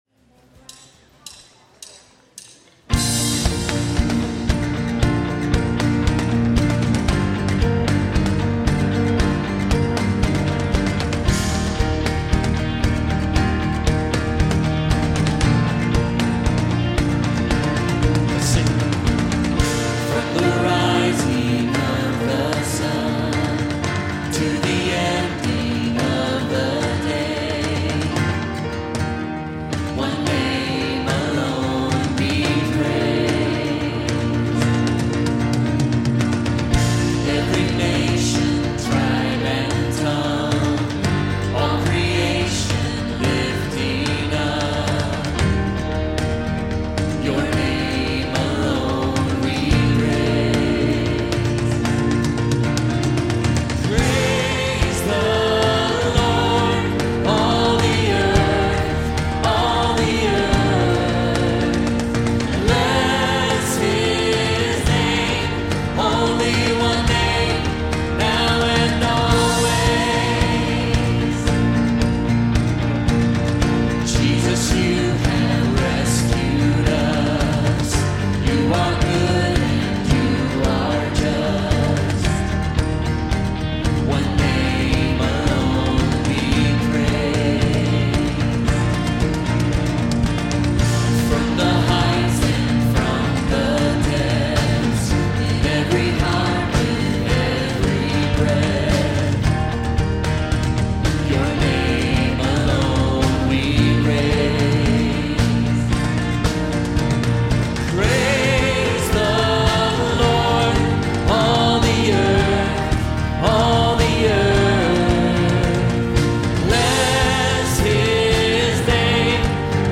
Evening Worship Service